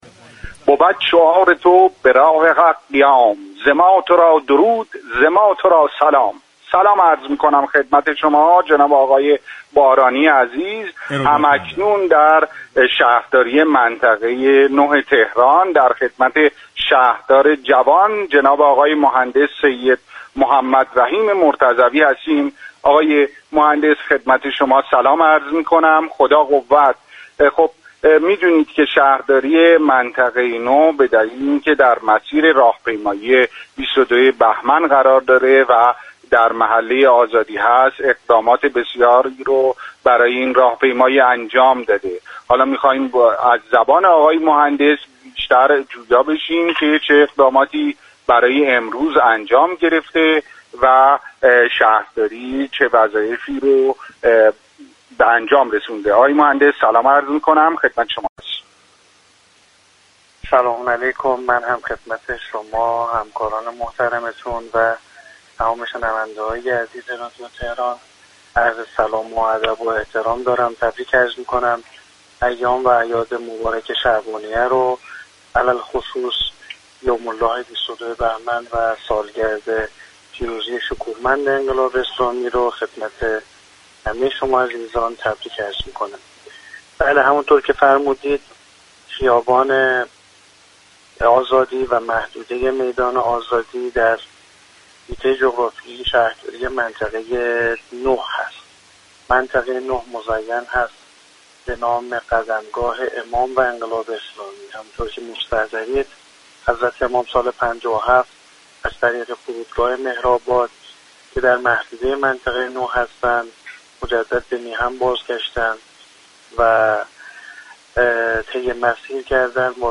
به گزارش پایگاه اطلاع رسانی رادیو تهران، سیدمحمد رحیم مرتضوی شهردار منطقه 9 تهران در گفت و گو با «اینجا تهران است» اظهار داشت: منطقه 9 شهرداری تهران قدمگاه امام خمینی (ره) و انقلاب اسلامی است.